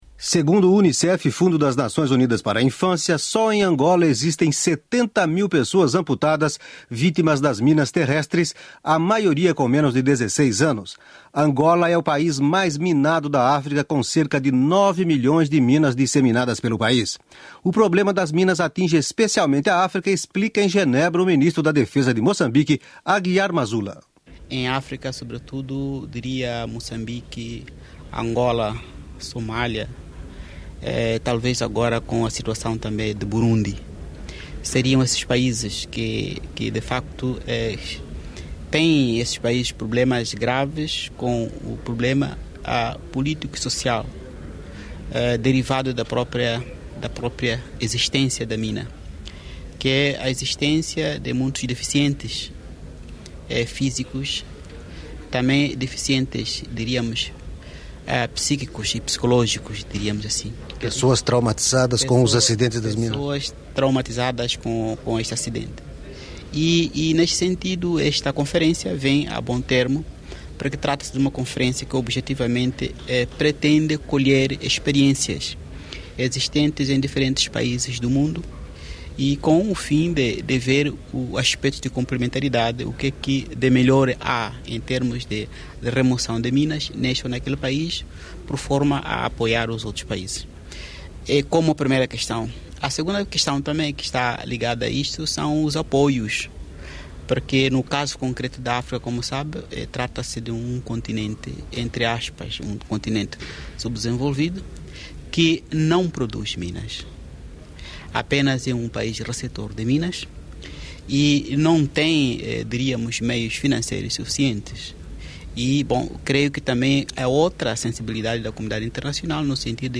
Arquivo da Rádio Suíça Internacional (agosto de 1995).